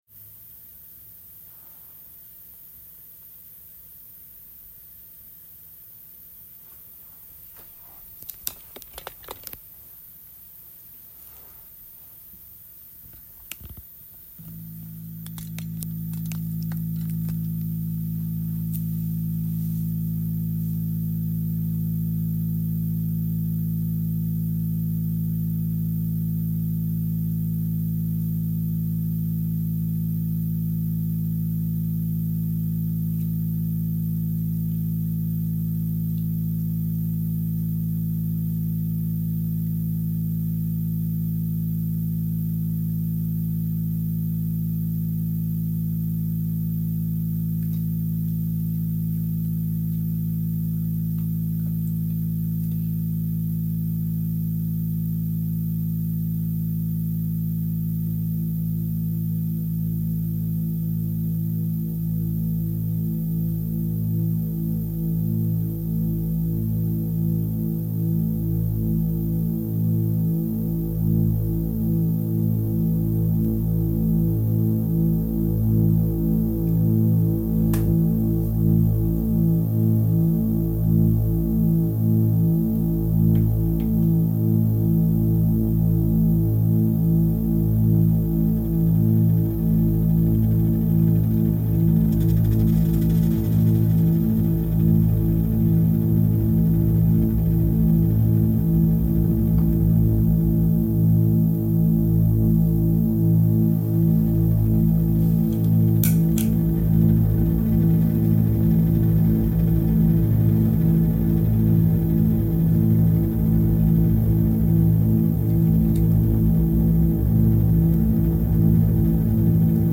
interview reimagined